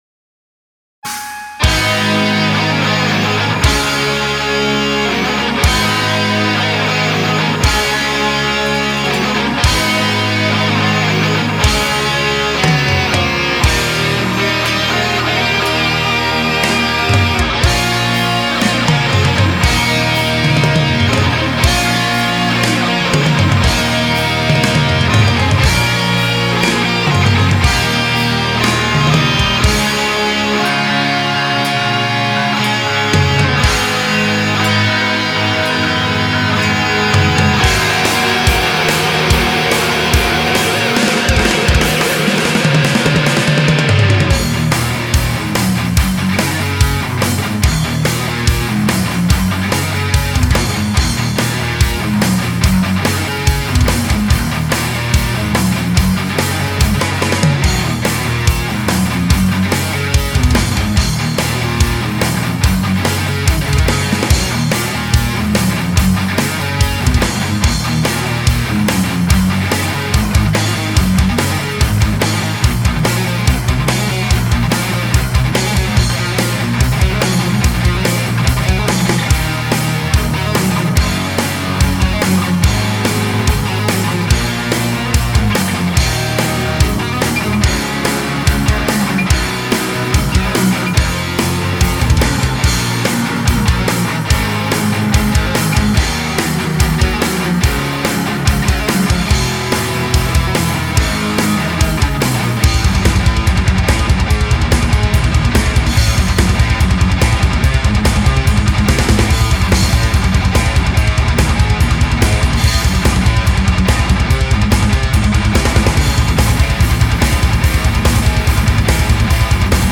гитары, бас, клавиши
ударные
флейта
кахон, шейкеры
Волшебная музыка.